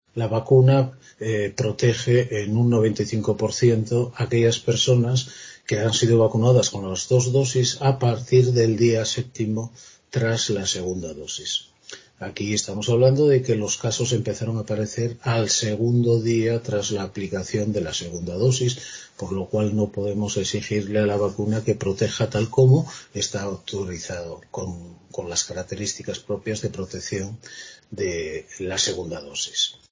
Mario Margolles explica porque siguen apareciendo brotes en personas vacunadas